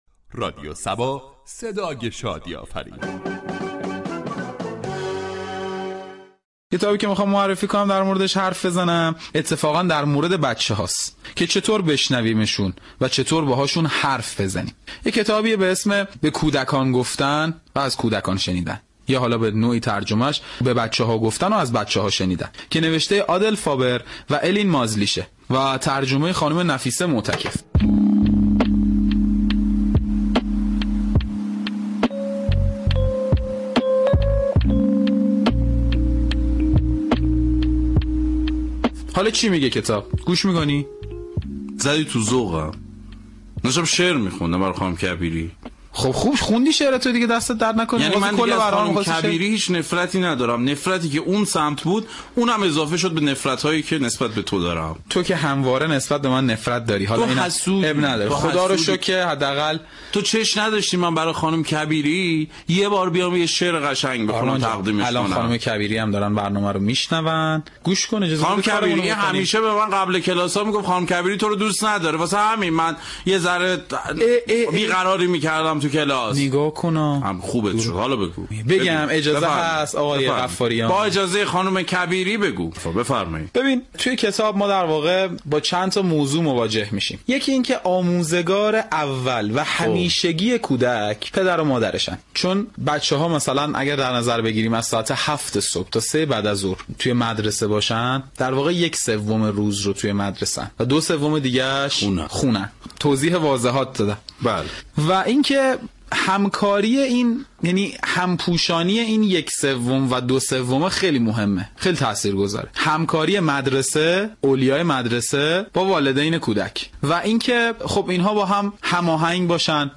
در این برنامه با نقد و بررسی رمان و در كنار آن پخش سكانس های از فیلم مخاطب به خواندن كتاب ترغیب می شود .